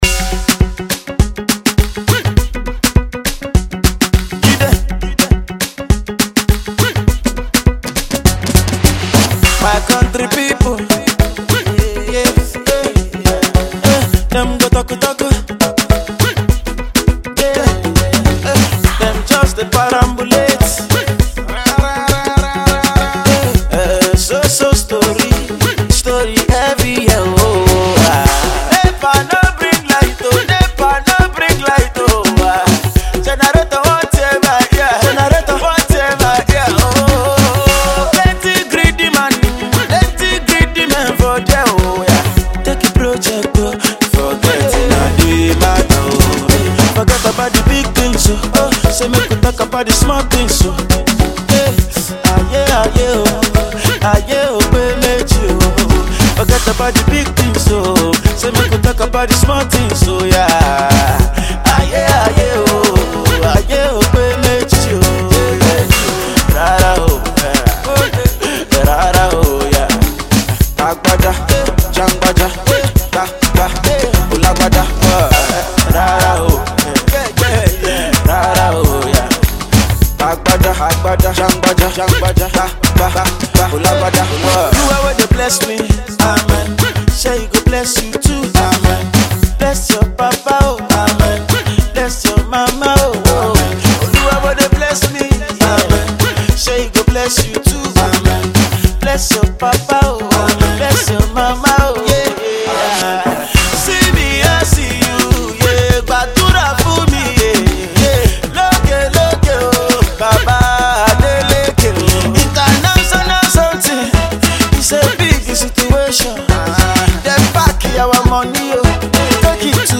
An Afrobeat tune